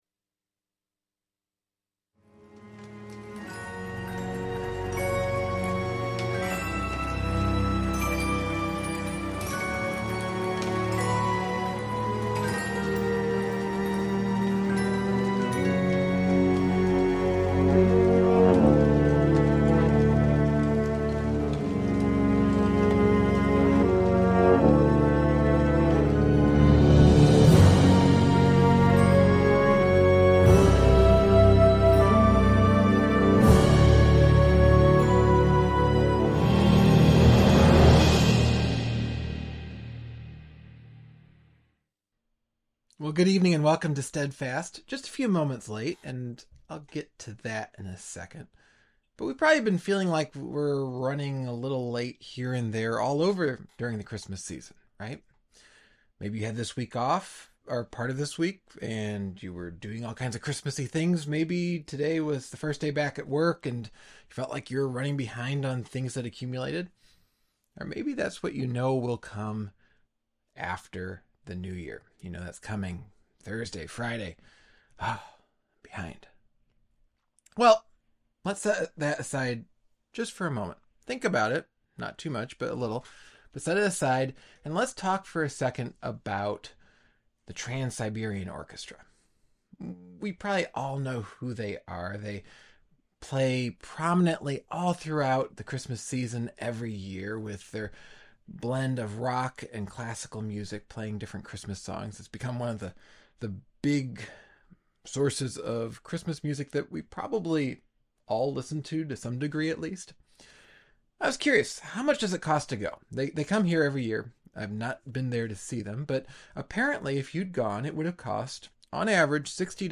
Local Church Messages